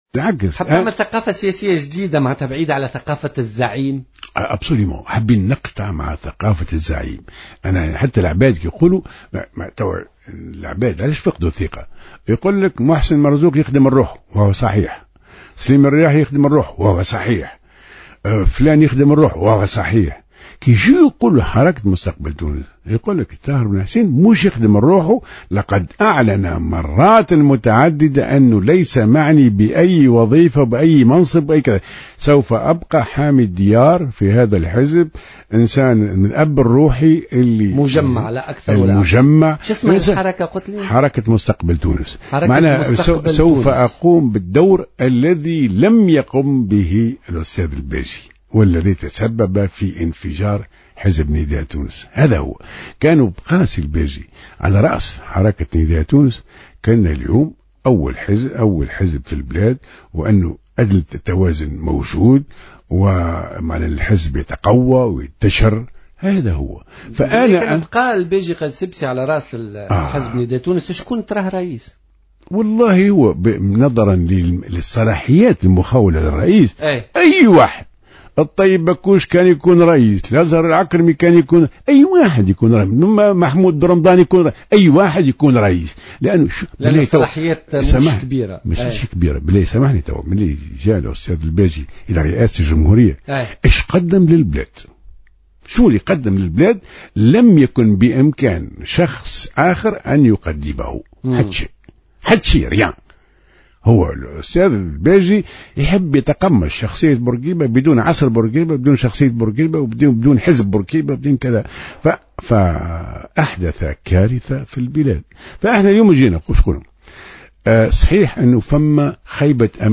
وأوضح بن حسين، ضيف برنامج "بوليتيكا" اليوم الخميس أنه "سيبقى حامي الديار" في هذا الحزب وسيقوم بالدور الذي لم يقم به الباجي قائد السبسي.